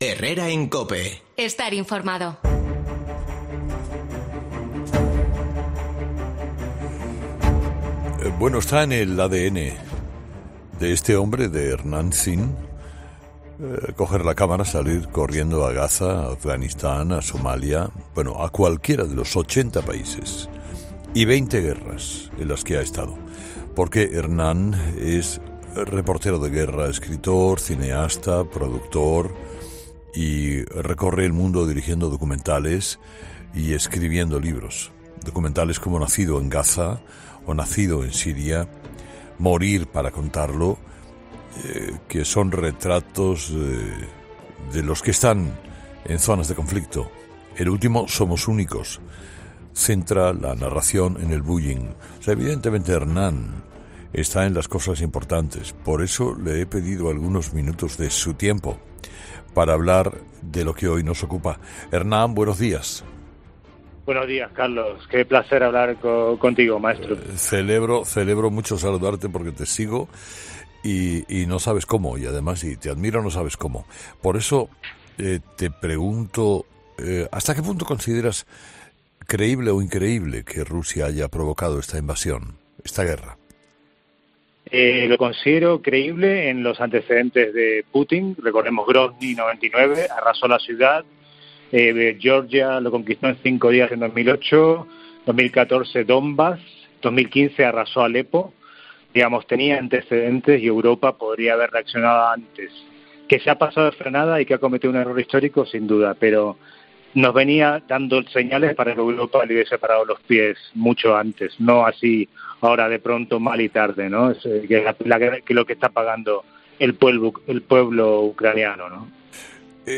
AUDIO: El cineasta habla con Carlos Herrera sobre la Guerra en Ucrania y las consecuencias que puede tener este conflicto en el resto del mundo